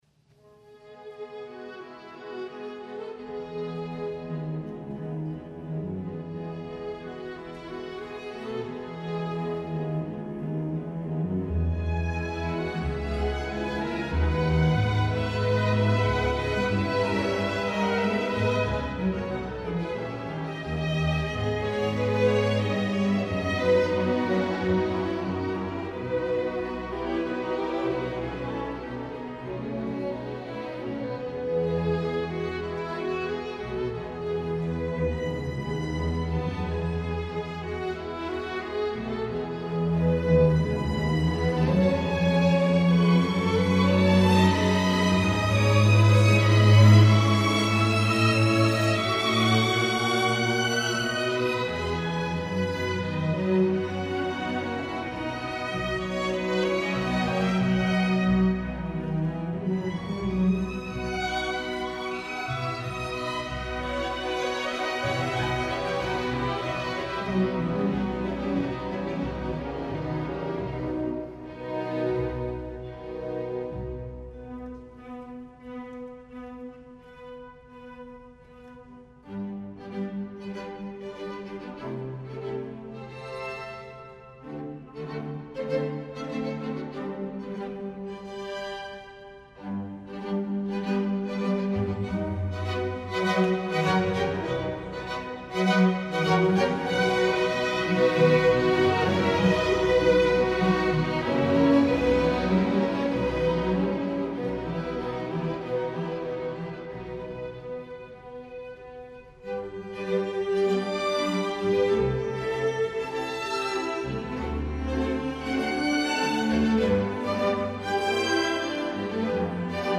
Ein Konzert in der Schlosskirche Bad Homburg 2007